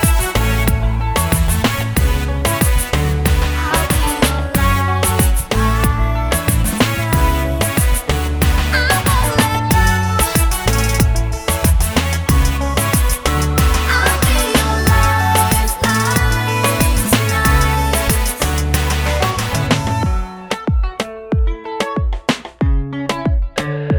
Duet Version Pop (2010s) 3:09 Buy £1.50